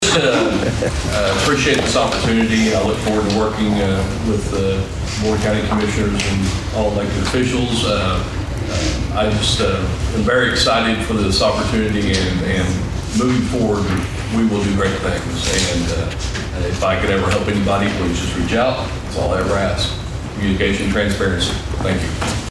During the citizen's input portion of the meeting, Perrier spoke on his excitement